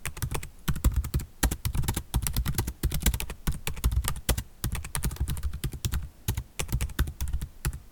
typing on laptop
fast keyboard laptop typing sound effect free sound royalty free Memes